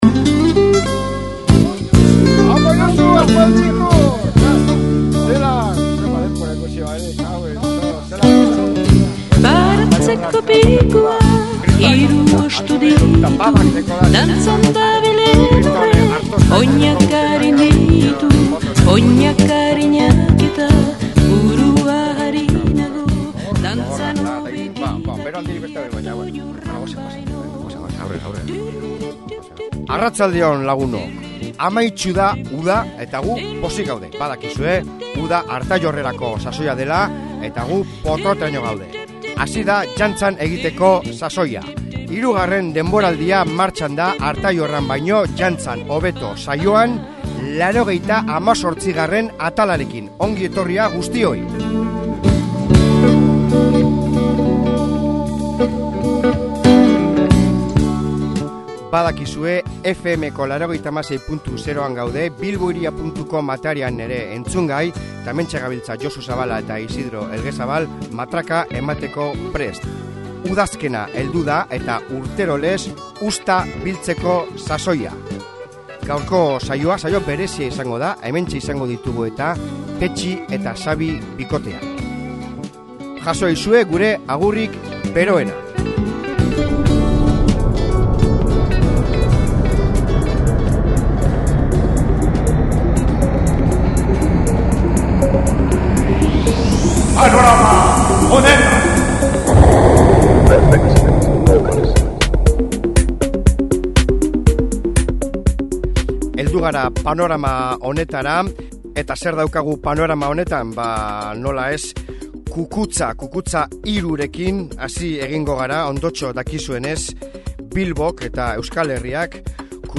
Bi monstruo hauen zuzeneko musika entzungo dugu, izatezko bikote honen zergatiak ezagutu eta proiektu berriak. 60 eta 70eko rock ´n roll basatia! Elkarrizketa luze-zabal interesgarria! bota atxurre!